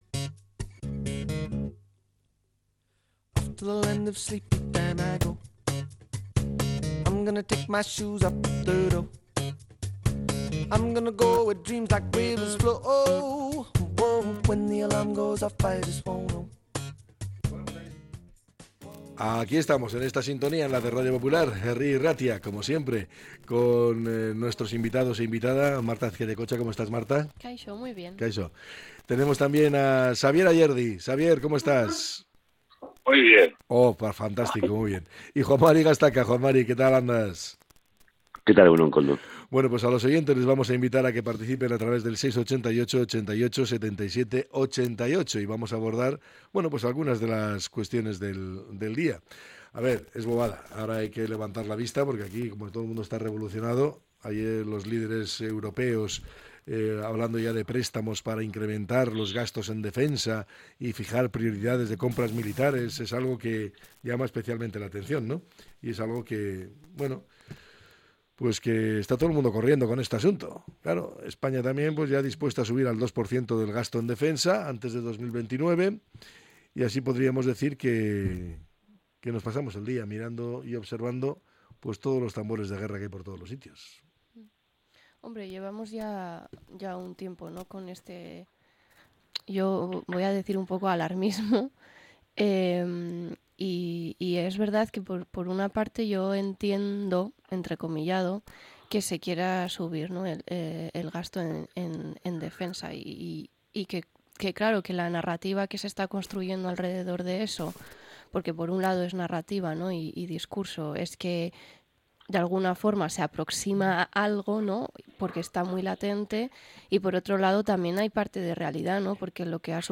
La tertulia 07-03-25.